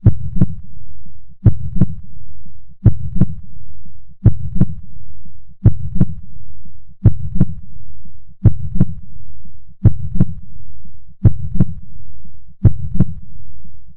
鼓動/心臓の音
heartbeats.mp3